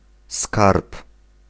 Ääntäminen
Synonyymit dictionnaire Ääntäminen France: IPA: /tʁe.zɔʁ/ Haettu sana löytyi näillä lähdekielillä: ranska Käännös Ääninäyte Substantiivit 1. skarb {m} Suku: m .